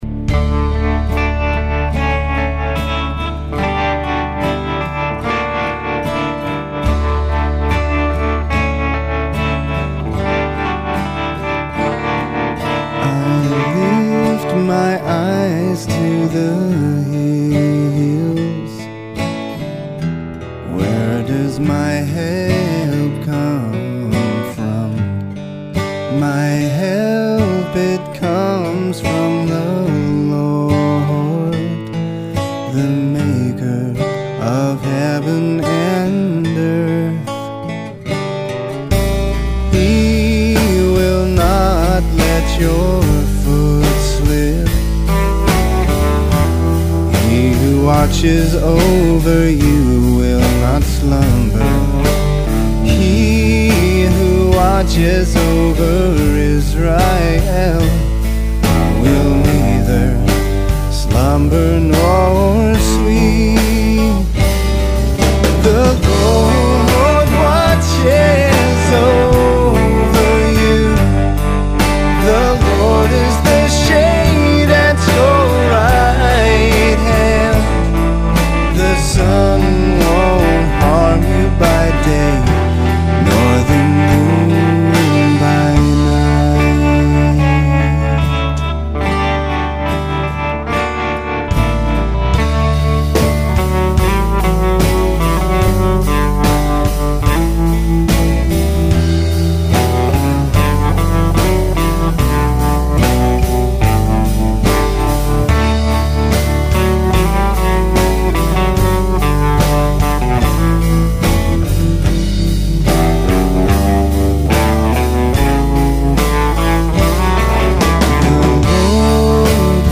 First rate pure rock